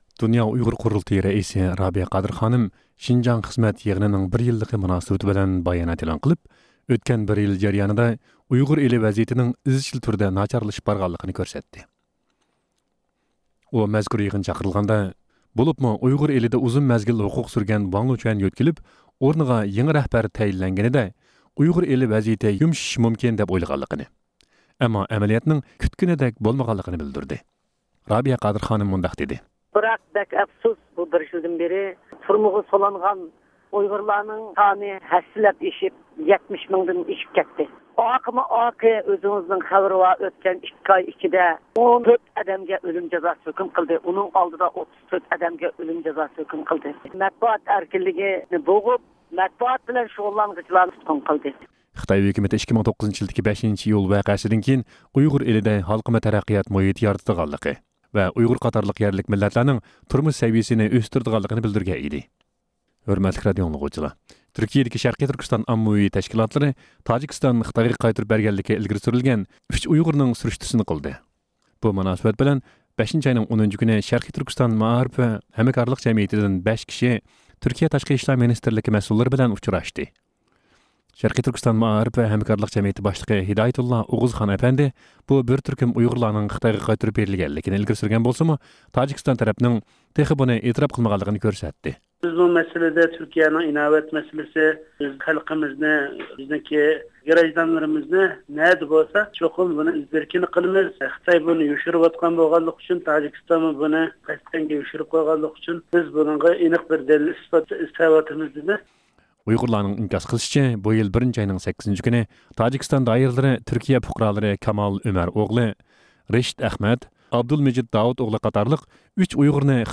ھەپتىلىك خەۋەرلەر (14-مايدىن 20-مايغىچە) – ئۇيغۇر مىللى ھەركىتى